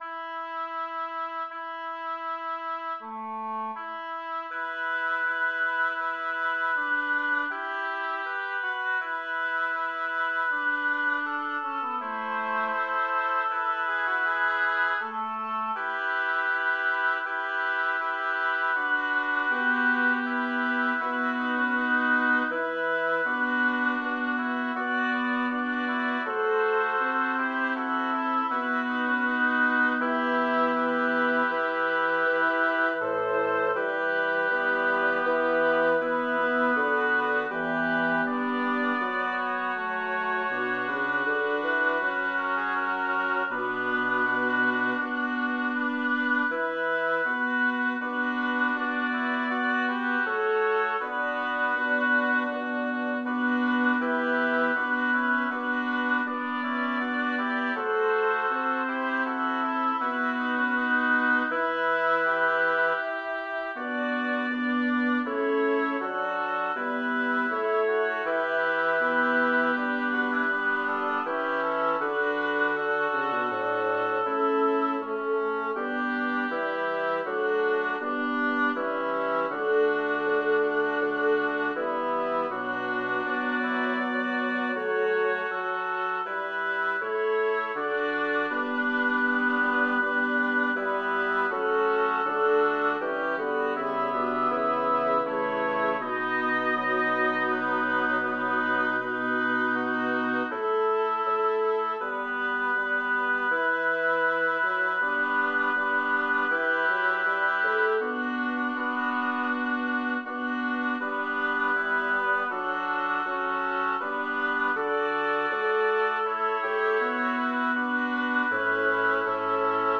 Title: Beatus Benedictus abbas Composer: Philippe Duc Lyricist: Number of voices: 5vv Voicing: SAATT Genre: Sacred, Motet
Language: Latin Instruments: A cappella